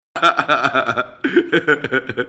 Play, download and share Vieze lach 4 original sound button!!!!
vieze-lach-4.mp3